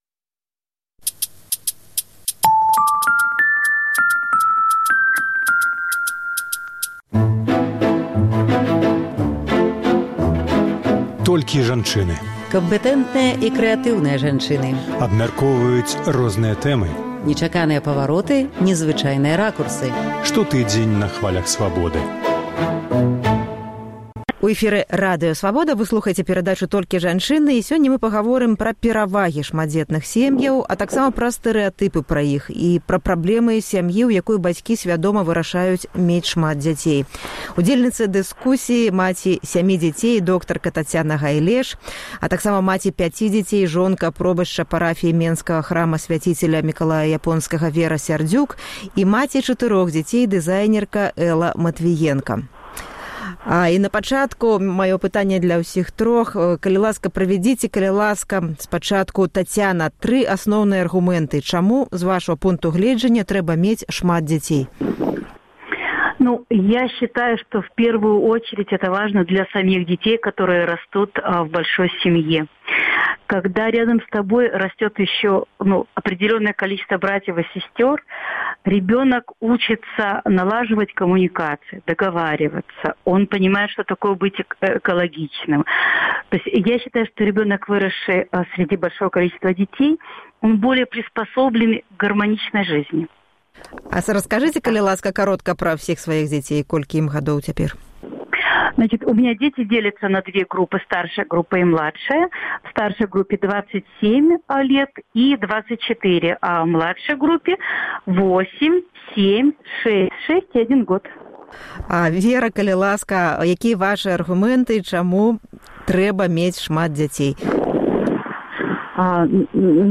У перадачы «Толькі жанчыны» гаворым пра перавагі і праблемы шматдзетных семʼяў, а таксама стэрэатыпы пра іх. Удзельніцы дыскусіі